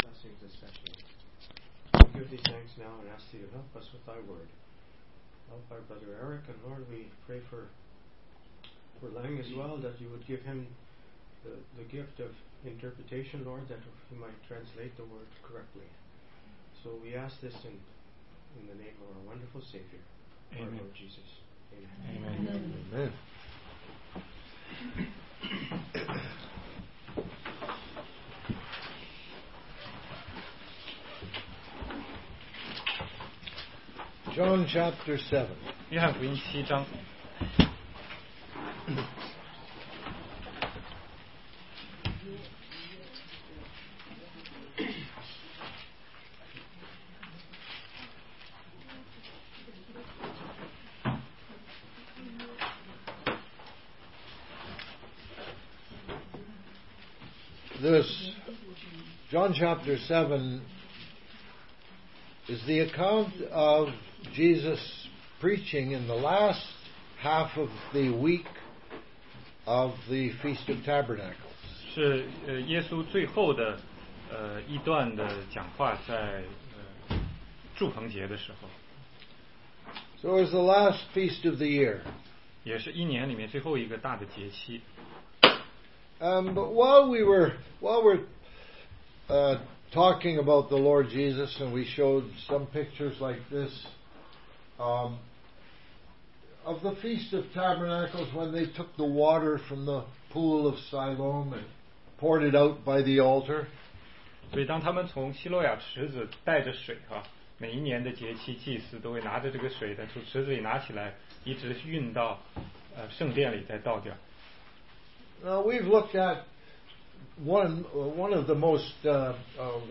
16街讲道录音 - 约翰福音7章37-52节